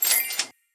Item Purchase (3).wav